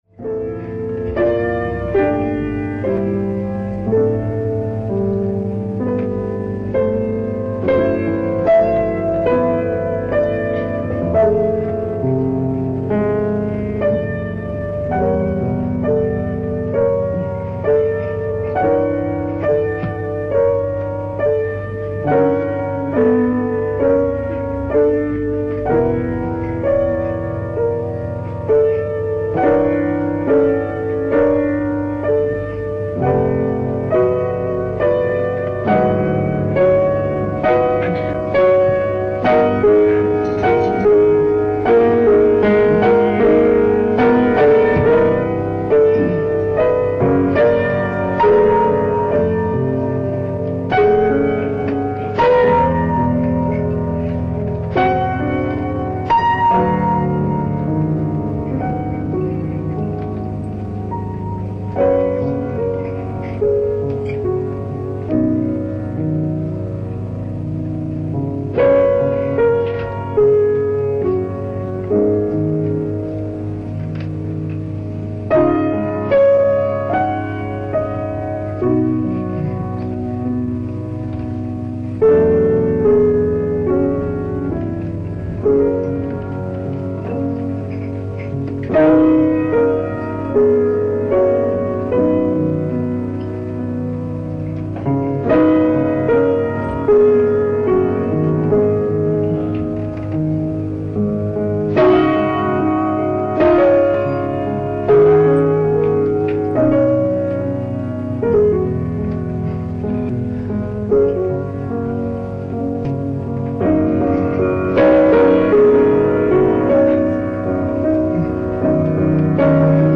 ライブ・アット・アポロ・シアター、パリ、フランス 05/24/1970
※試聴用に実際より音質を落としています。